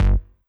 CLF Bass G.wav